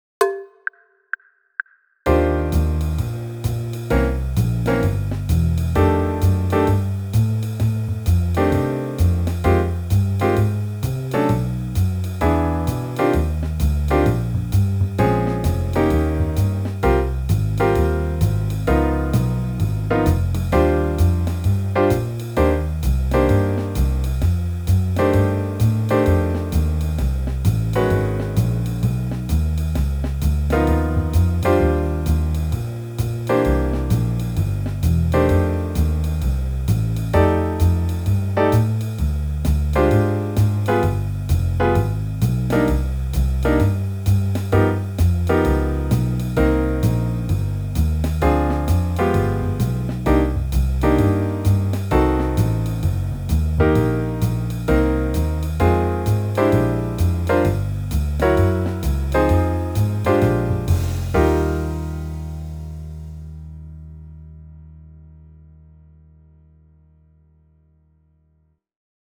this practice-with-me track for TENOR SAX covers 2019 TMEA Jazz Sax Etude 1, mm. 1-16. i’m using the iReal Pro app. check out more about it here!
2019-tmea-jazz-audition-sax-etude-1.wav